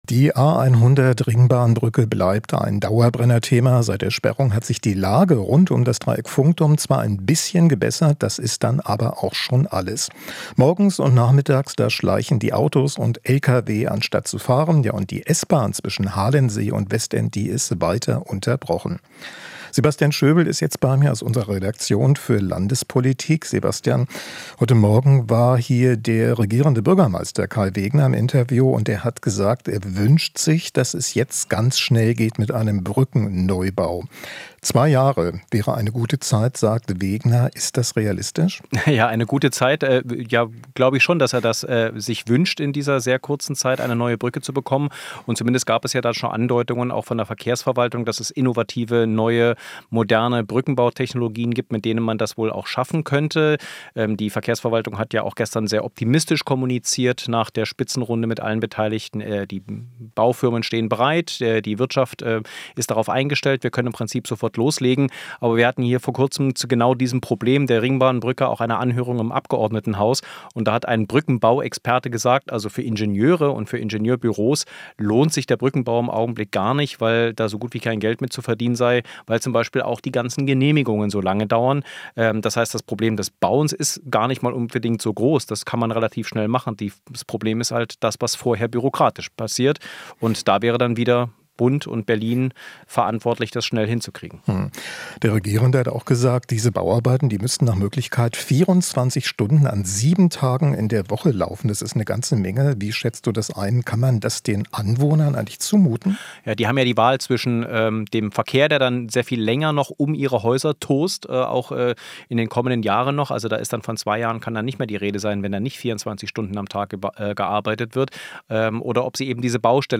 Interview - Neubau der Ringbahn-Brücke - und seine Folgen